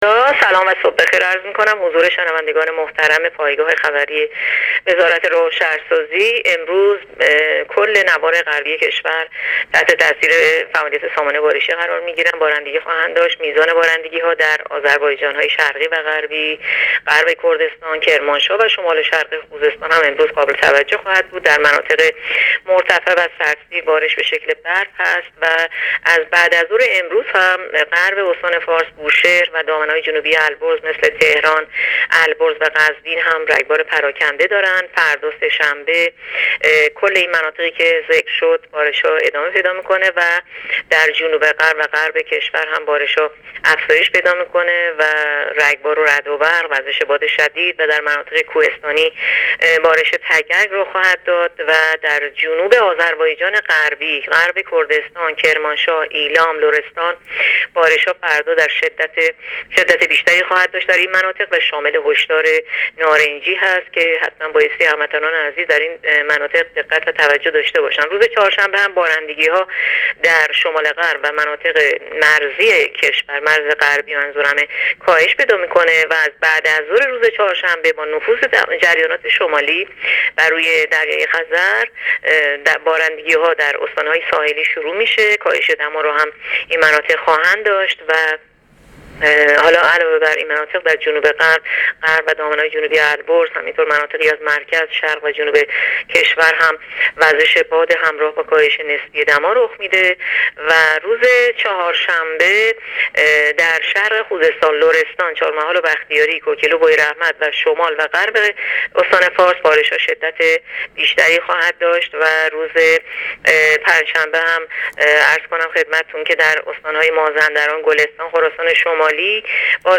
گزارش رادیو اینترنتی پایگاه‌ خبری از آخرین وضعیت آب‌وهوای ۵ آذر؛